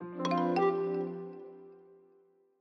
Longhorn Ten Alfa - Notify Email.wav